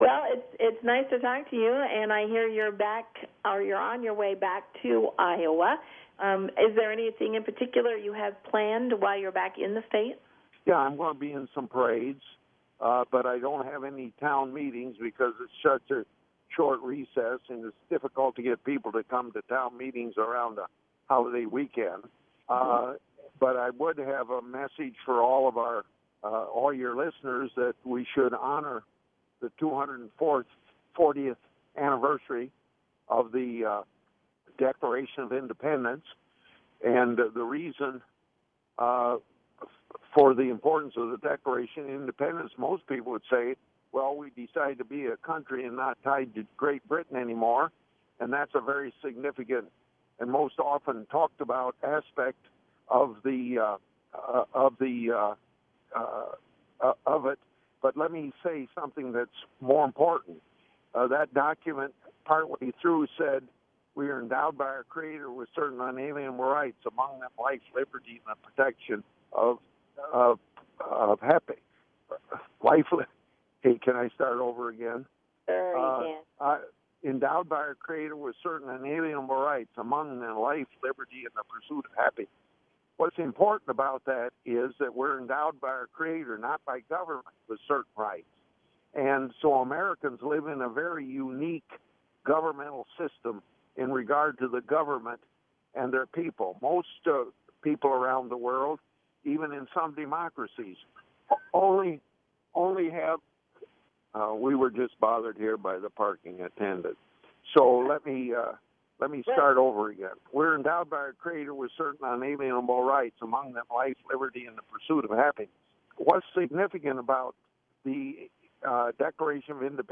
Public Affairs Program, 6-30-16, KILJ.mp3